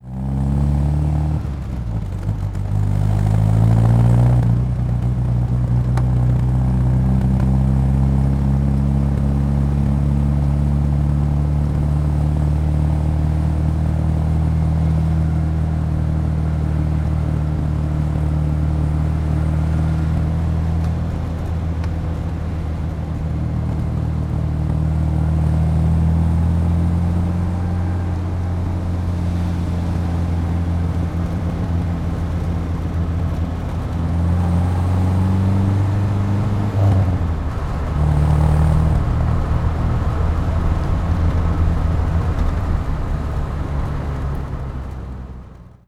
Tubo de escape de un coche Triumph en funcionamiento continuo